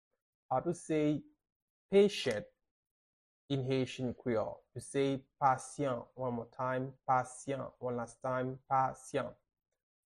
How to say "Patient" in Haitian Creole - "Pasyan" pronunciation by a native Haitian teacher
“Pasyan” Pronunciation in Haitian Creole by a native Haitian can be heard in the audio here or in the video below:
How-to-say-Patient-in-Haitian-Creole-Pasyan-pronunciation-by-a-native-Haitian-teacher.mp3